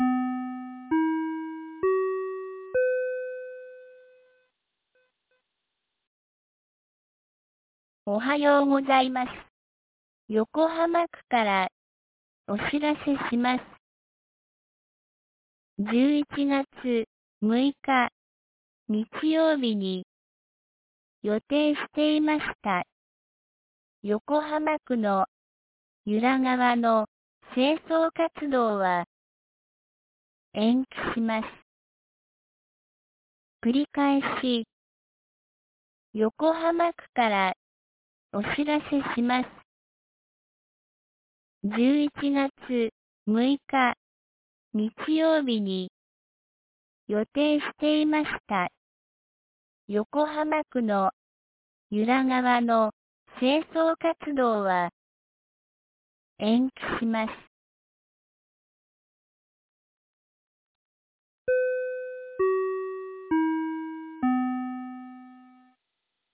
2022年11月03日 07時52分に、由良町から横浜地区へ放送がありました。